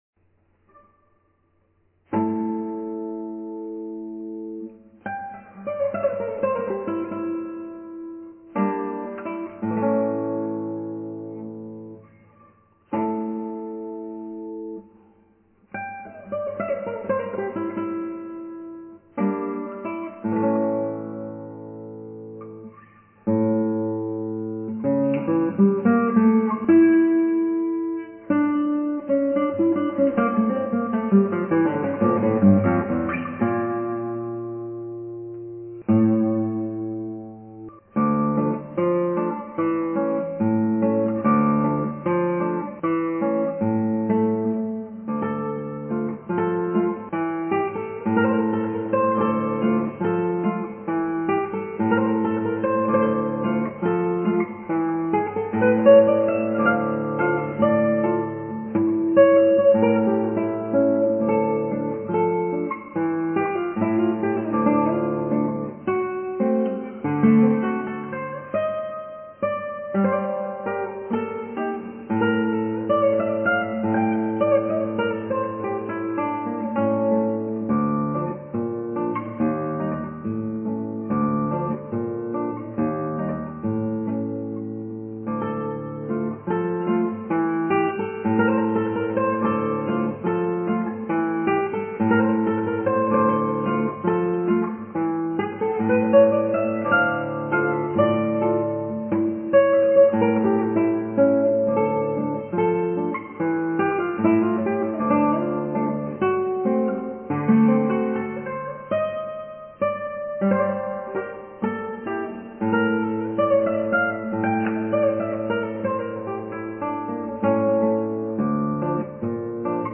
アラビア風奇想曲、バルベロで